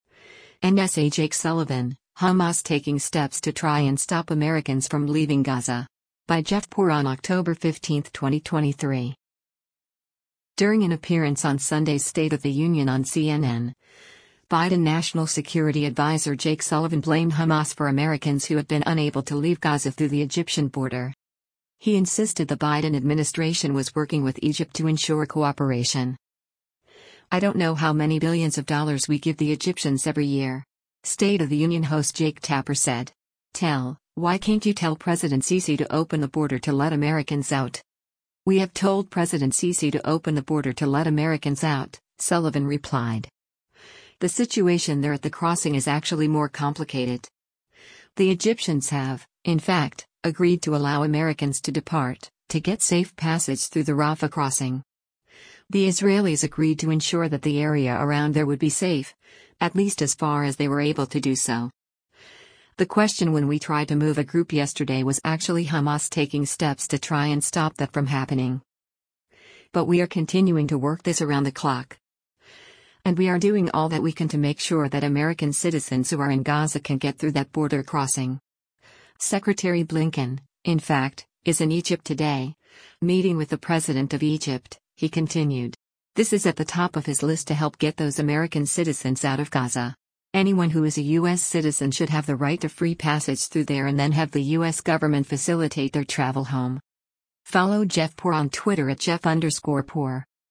During an appearance on Sunday’s “State of the Union” on CNN, Biden National Security Adviser Jake Sullivan blamed Hamas for Americans who have been unable to leave Gaza through the Egyptian border.